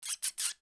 • Hamster Calls
To add to the player’s interest in controlling the hamster, I recorded a series of hamster calls using a human voice and set them to play randomly when the hamster hit the wall in each room.
Hamster_Hiss_1-2.wav